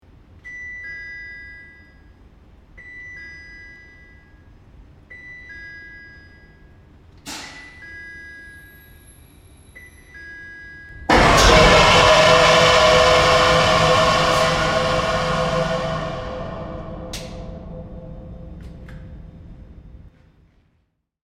Shock Fire; Warning Beeps, Heavy Power Surge And Whoosh